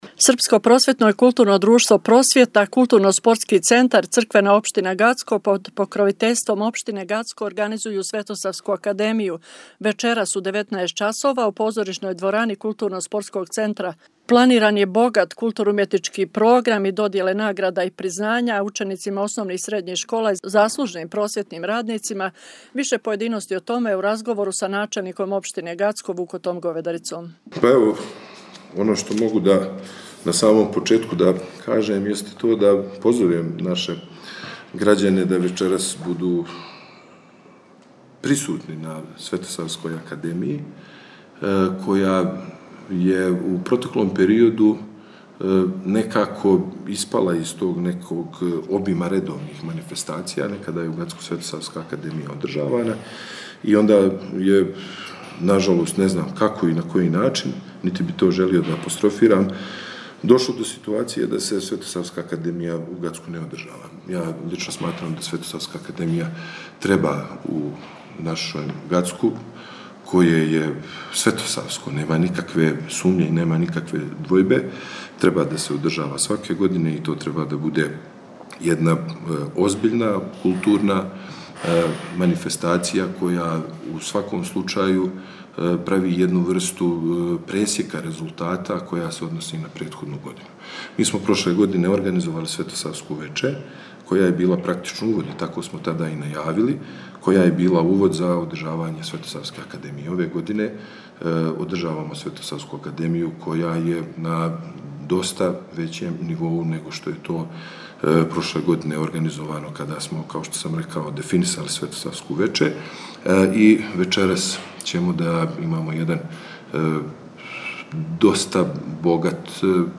Више појединости у разговору са начелником општине Гацко Вукотом Говедарицом: